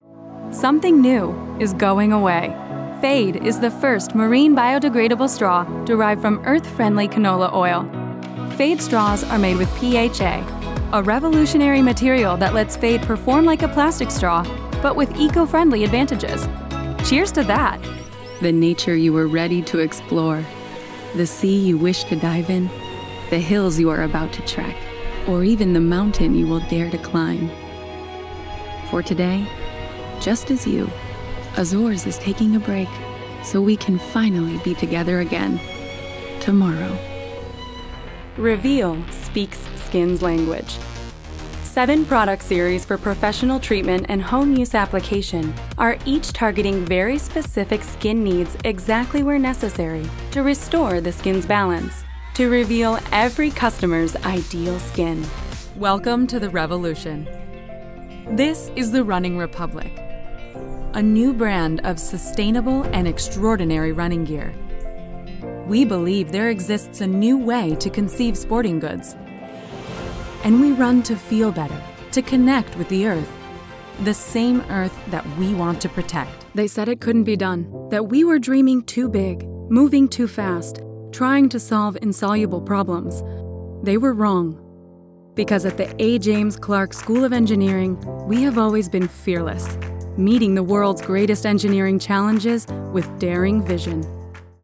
Image Film Narration
My voice has been characterized as clear, fresh and pleasant. My sound is youthful, intelligent, calming, authentic and quite versatile.